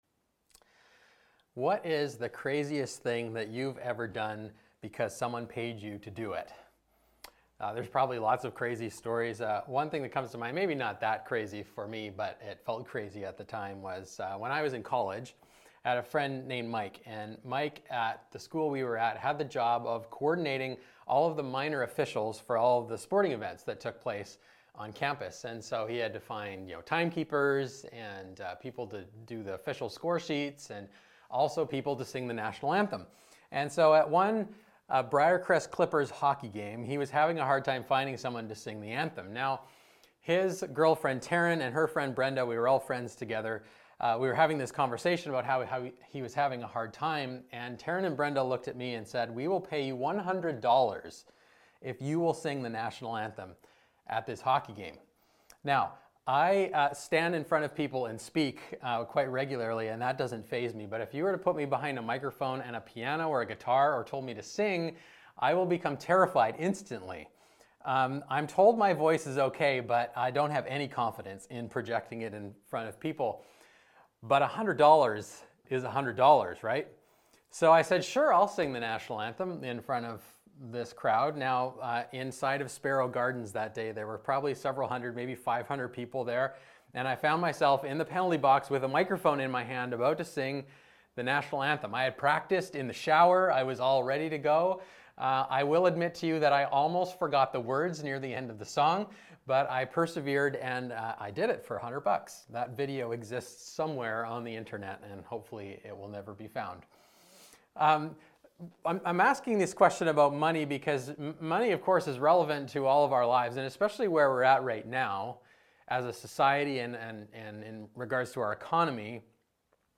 Download Download Reference Luke 12:13-34 SERMON NOTES Fools fix their eyes on the temporary; wise people live in light of the generosity of God’s Kingdom.”